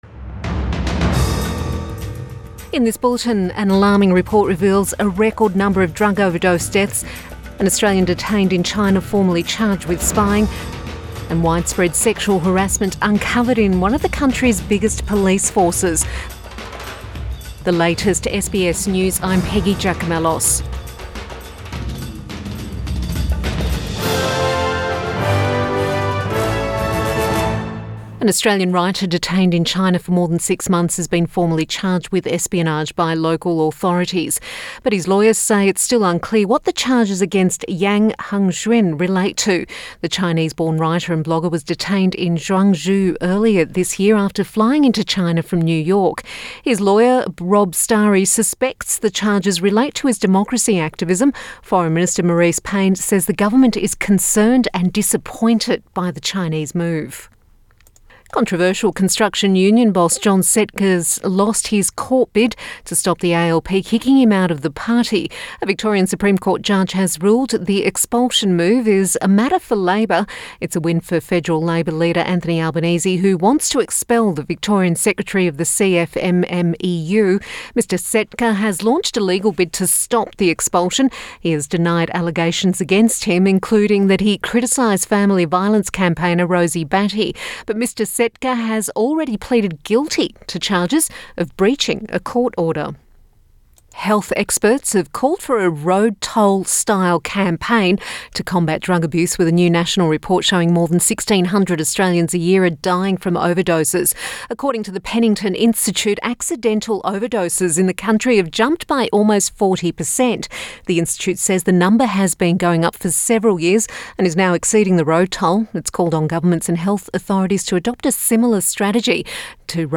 Midday bulletin 27 August 2019